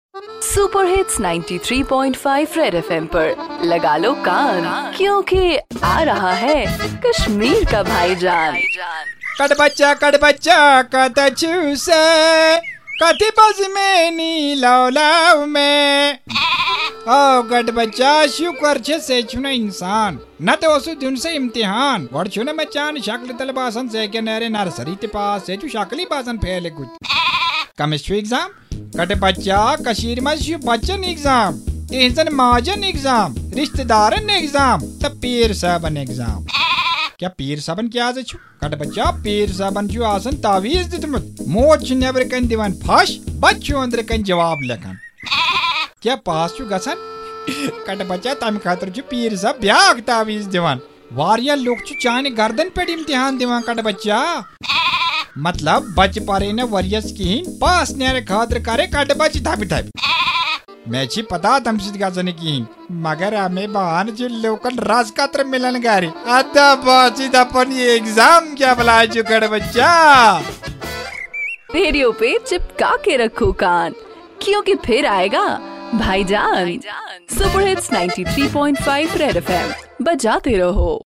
Bhaijaan the ultimate dose of comedy in Kashmir which is high on satire and humor .